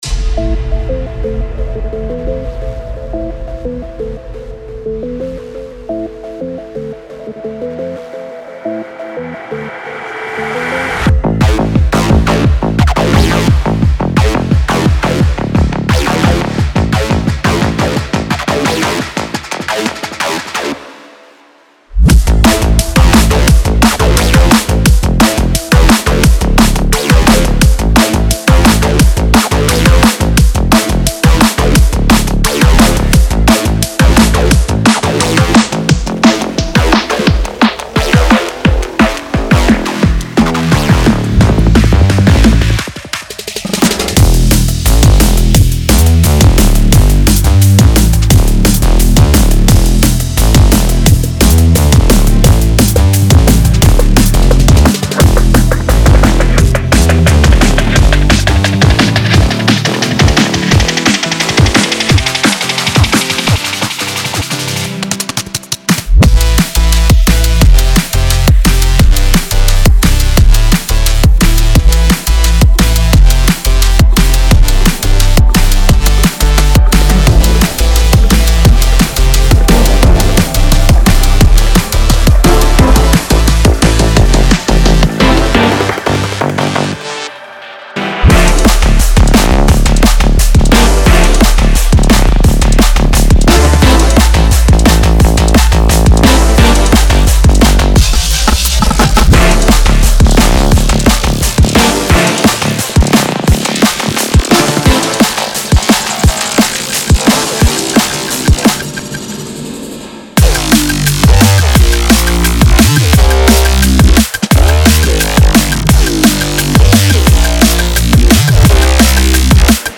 Genre:Drum and Bass
ディープなベースライン、ドラム、エフェクトから、パッド、アルペジオ、リードまで、必要なすべてが揃っています。
パンチのあるトランジェント、クリーンなローエンドの再現性、ミックス即戦力の完成度に強く重点が置かれています。
デモサウンドはコチラ↓